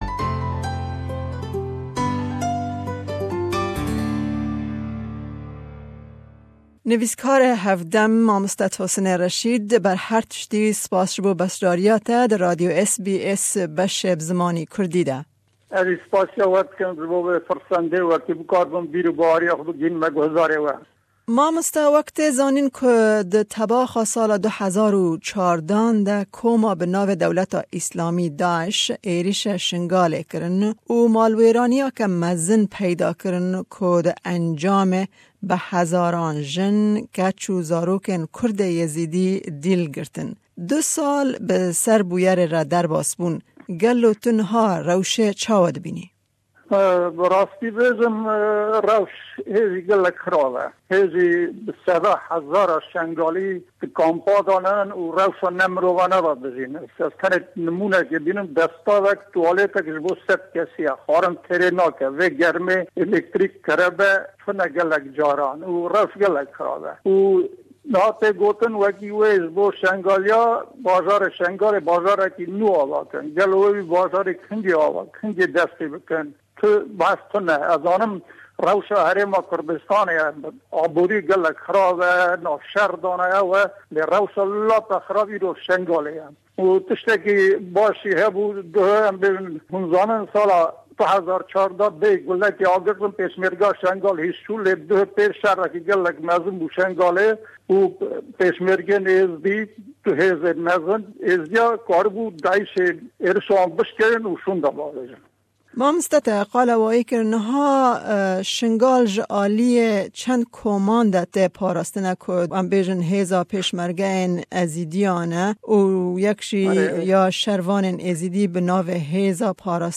me derbarî wê mijarê hevpeyvînek bi wî re pêk anî.